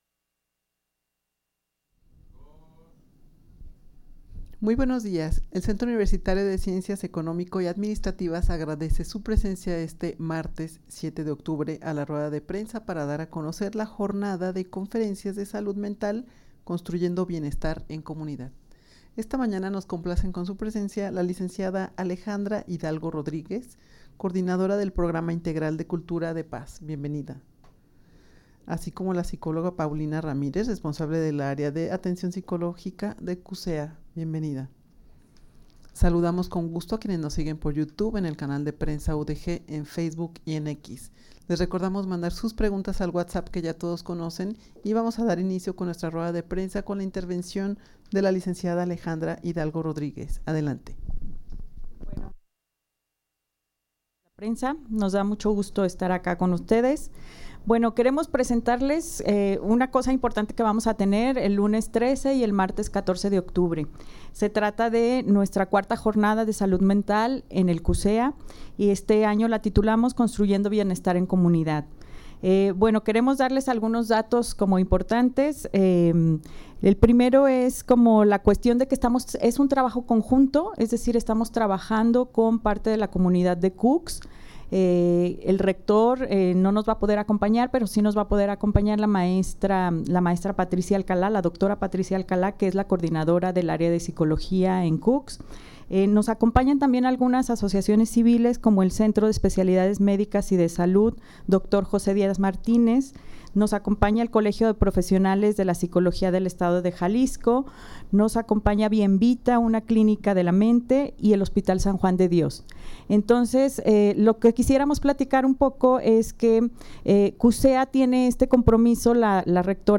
Audio de la Rueda de Prensa
rueda-de-prensa-para-dar-a-conocer-la-jornada-de-conferencias-de-salud-mental.mp3